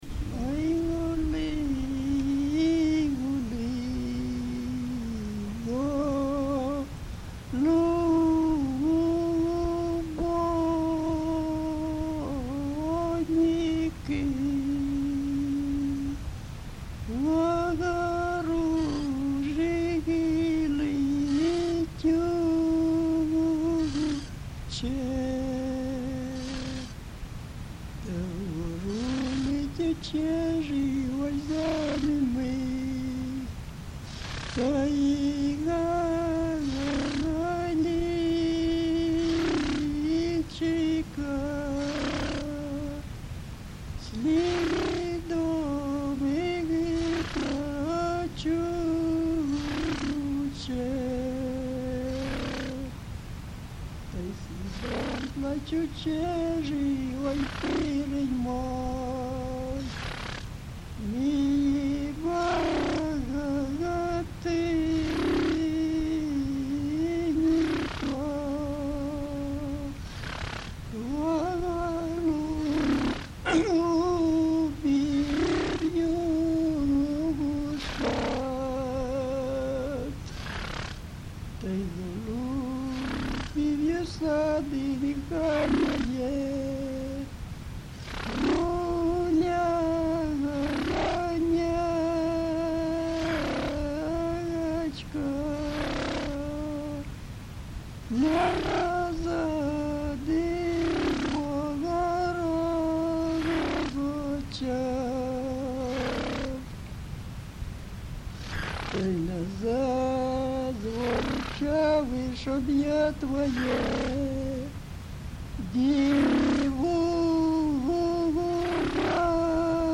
ЖанрВесільні
Місце записус. Єлизаветівка, Лозівський район, Харківська обл., Україна, Слобожанщина